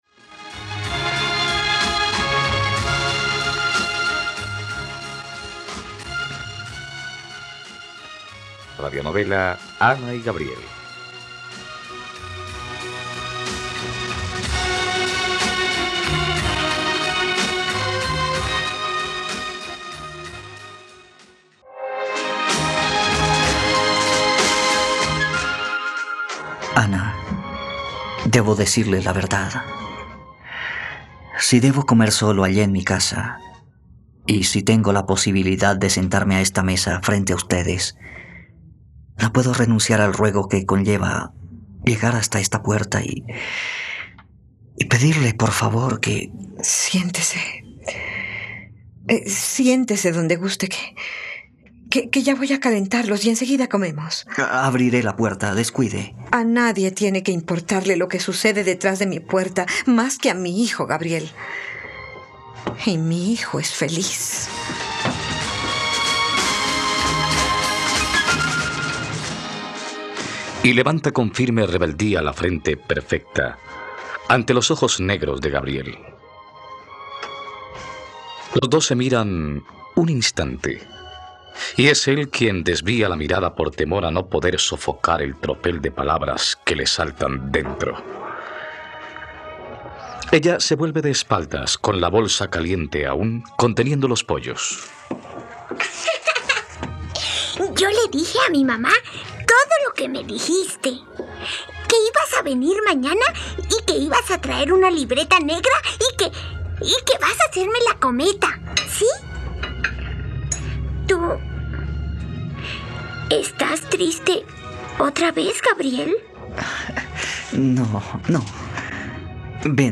..Radionovela. Escucha ahora el capítulo 38 de la historia de amor de Ana y Gabriel en la plataforma de streaming de los colombianos: RTVCPlay.